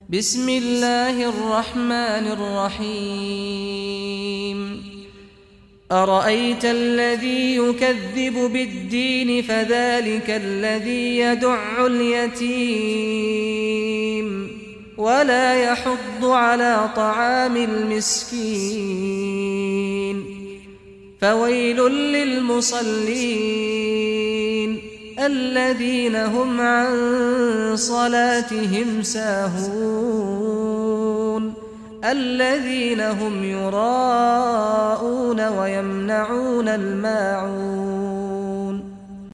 Surah Maun Recitation by Sheikh Saad Ghamdi
Surah Maun, listen or play online mp3 tilawat / recitation in Arabic in the beautiful voice of Sheikh Saad al Ghamdi.